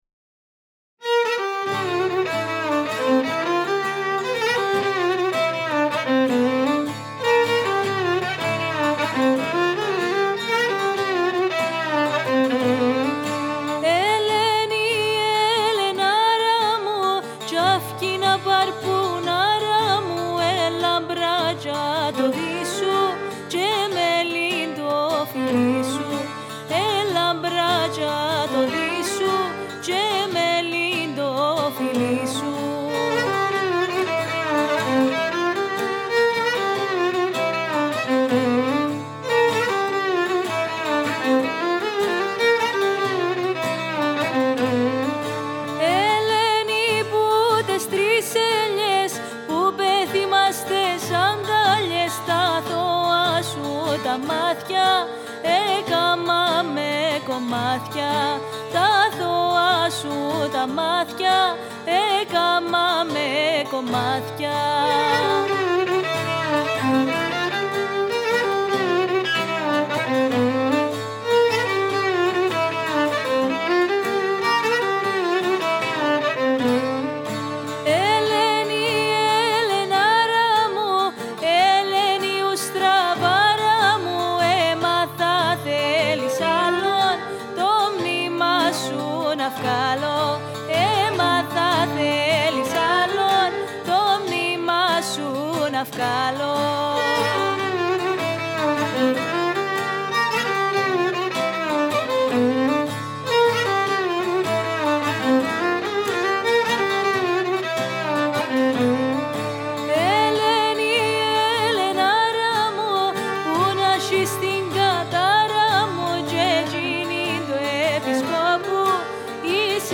Η κυπριακή παραδοσιακή μουσική